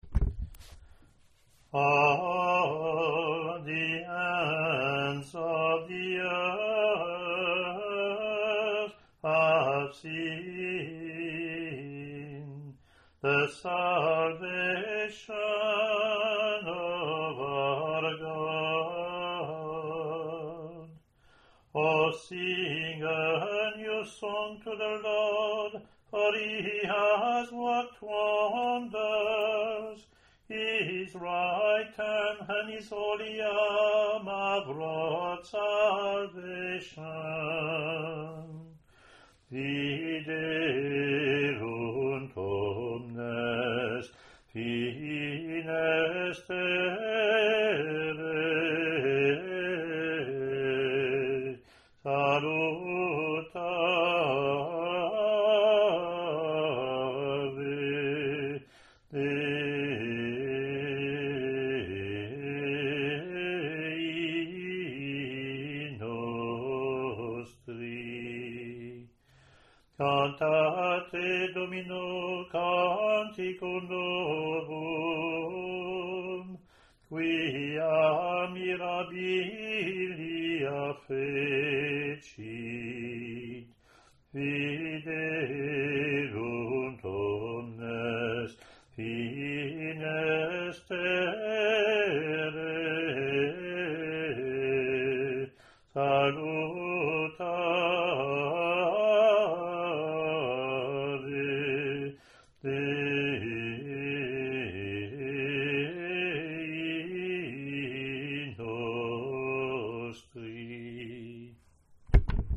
Communion (English antiphon – English verse – Latin antiphon)